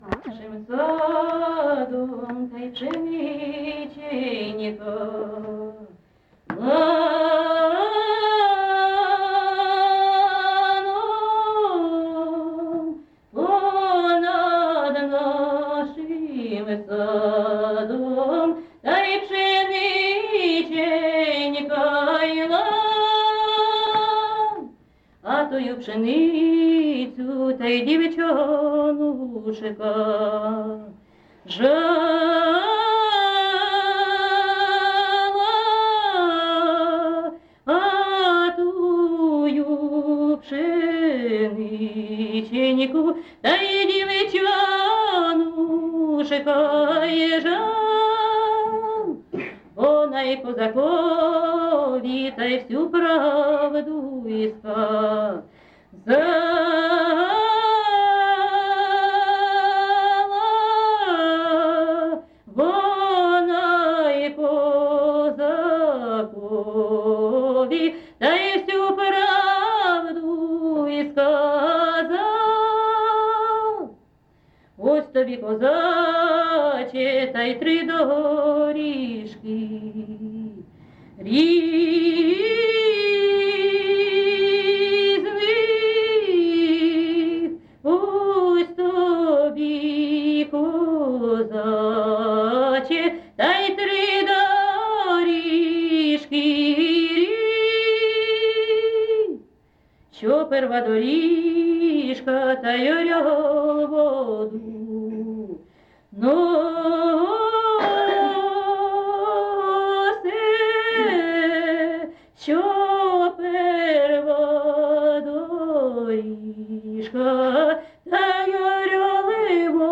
ЖанрПісні з особистого та родинного життя
Місце записус. Одноробівка, Золочівський район, Харківська обл., Україна, Слобожанщина